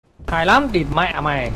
Play, download and share Hay lam dmm original sound button!!!!
cs-go-c4-funny-moments-hay-lam-mm-mp3cut.mp3